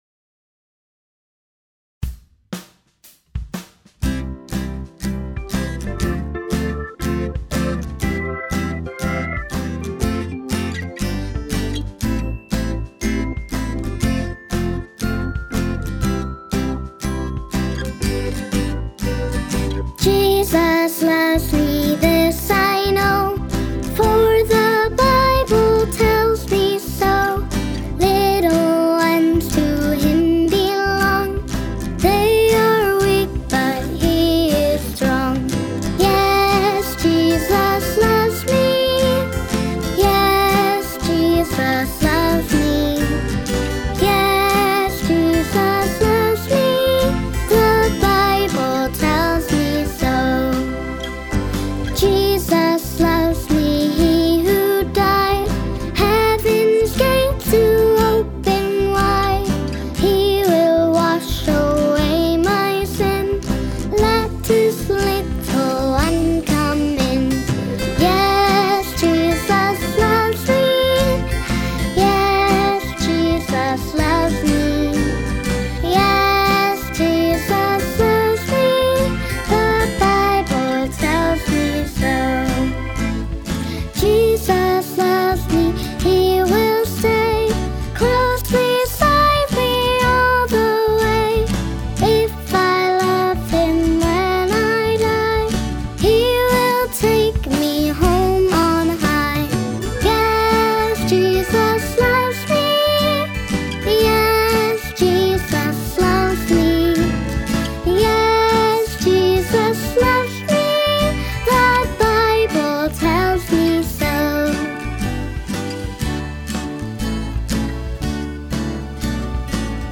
Backing track